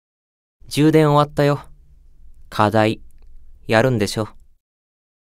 Belphegor_AP_Notification_Voice.ogg